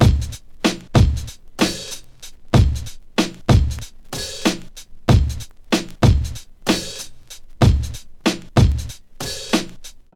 • 94.5 G# vinyl drumloop.wav
A nice vinyl sampled drumloop I found doing some crate digging - bpm is 94.5 and kick root key is G#.
94.5_G_sharp__vinyl_drumloop_AkF.wav